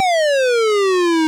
fsDOS_tankFall.wav